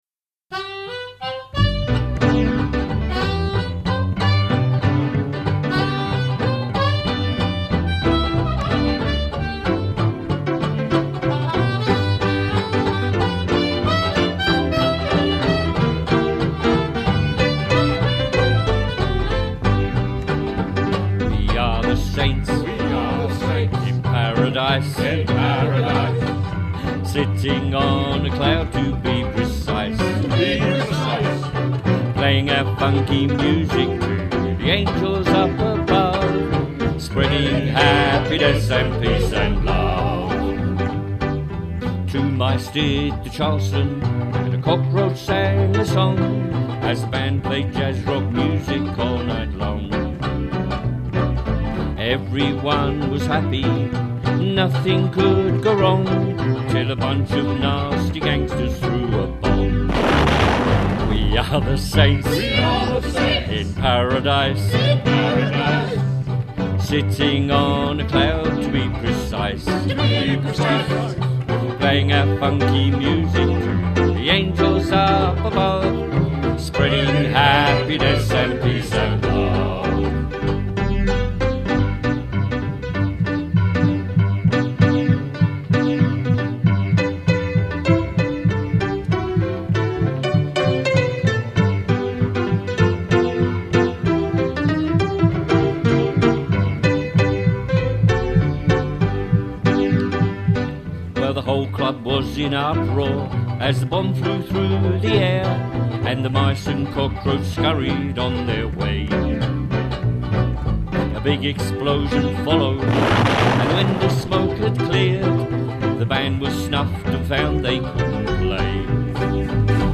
"Saints In Paradise" Recording Session
banjo
double bass
keys
soprano saxophone